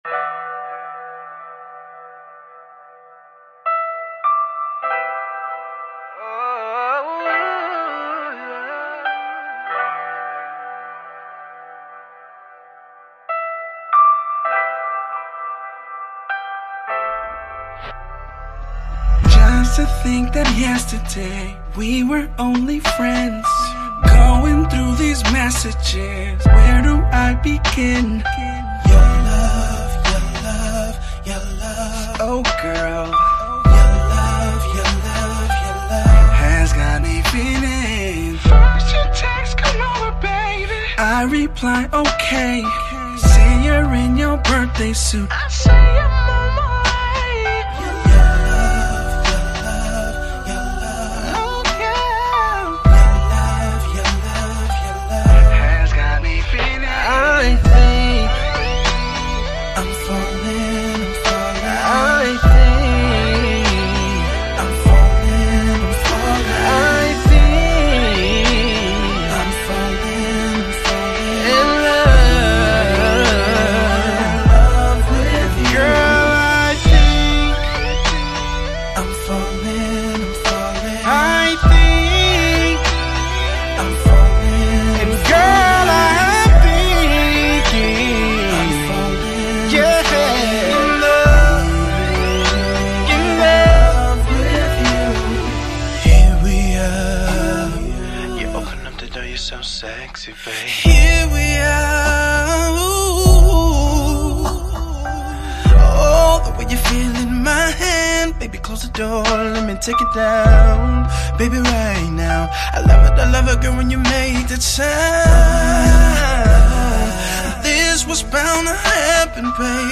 RnB
is a rapidly rising R&B group that hails from Milwaukee, WI.